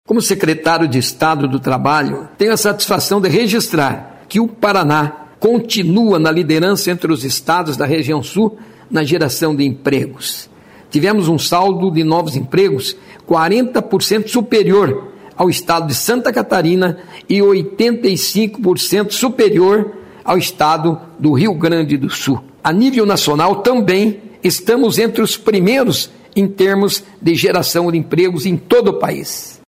Sonora do secretário do Trabalho, Qualificação e Renda, Mauro Moraes, sobre o Paraná ser o maior empregador da região Sul e o quarto maior do País